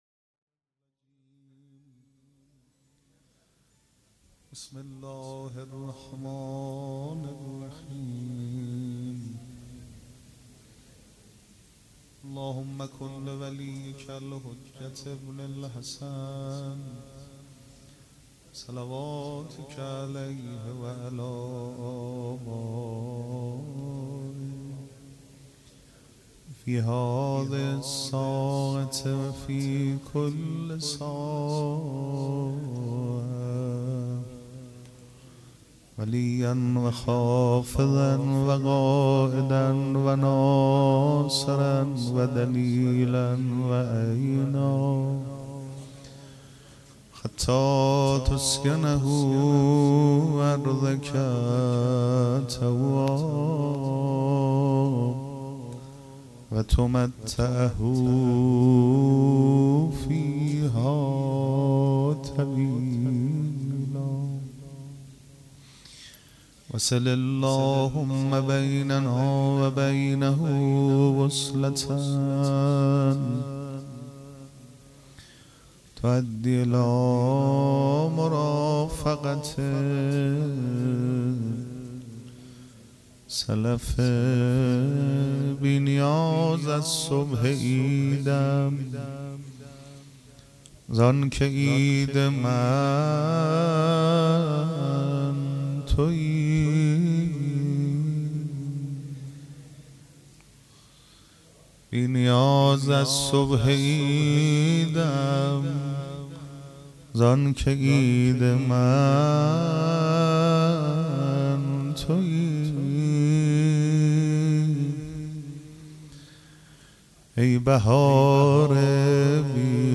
پیش منبر
مراسم جشن ولادت سرداران کربلا (شب دوم)